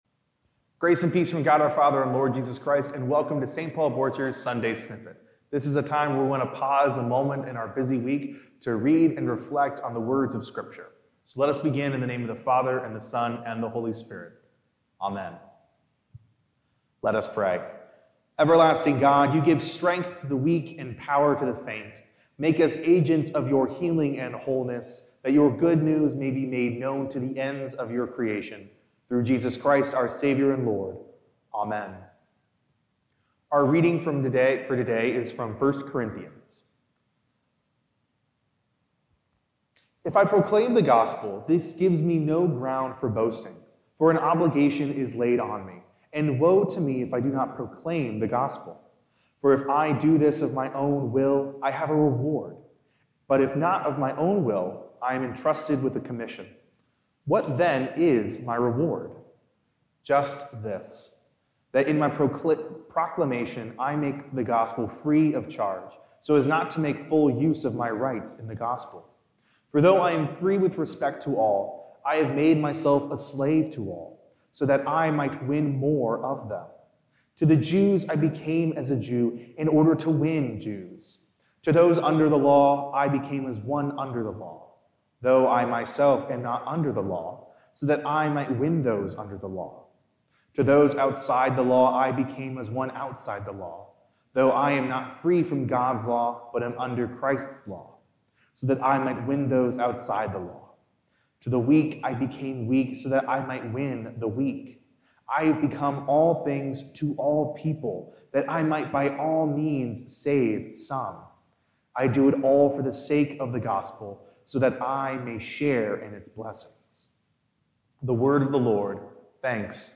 Recorded by the staff and volunteers at St. Paul Lutheran Church - Borchers in Seymour, Indiana.